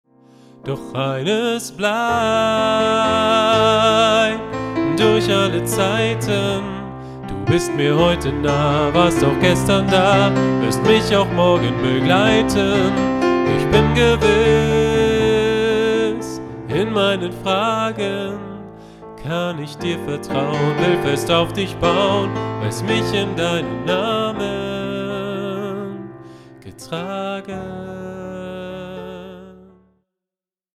Notation: SATB
Tonart: D, F
Taktart: 4/4, 3/4
Tempo: 100, 120 bpm
Parts: 2 Verse, Refrain, Bridge